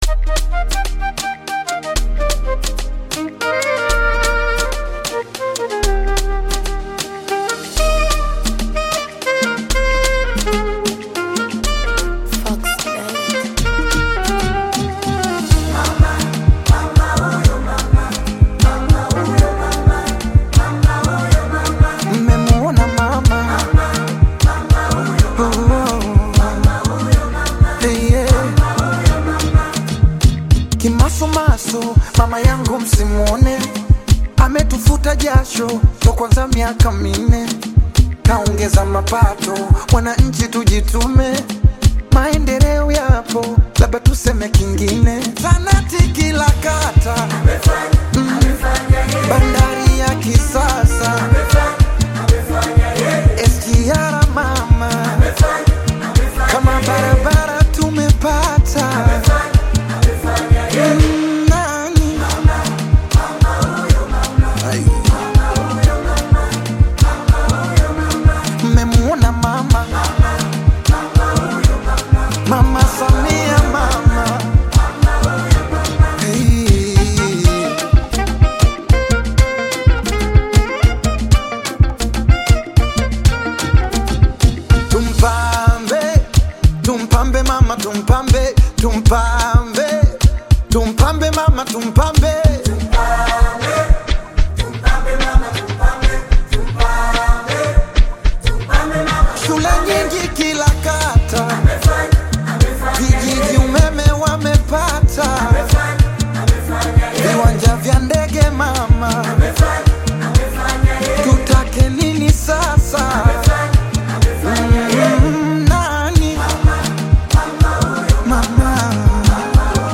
Bongo Flava
Bongo Flava song